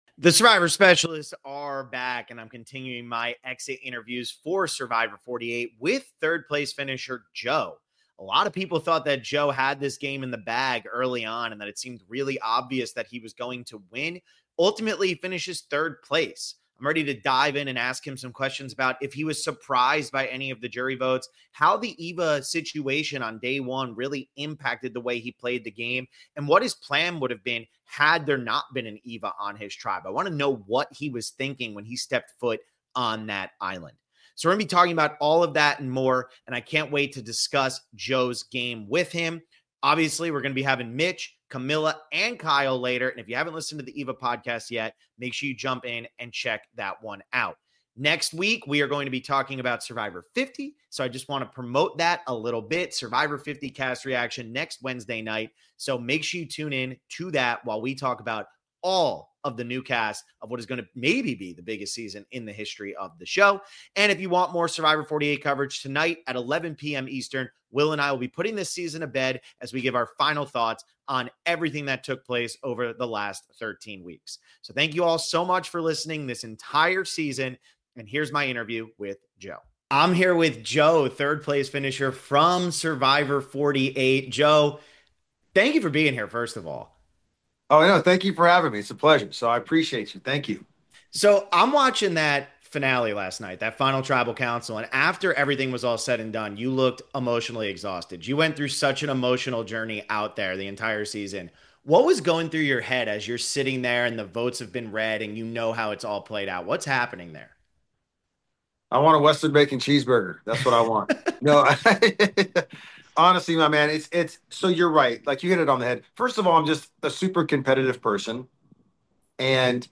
Survivor 48 Post Game Interview w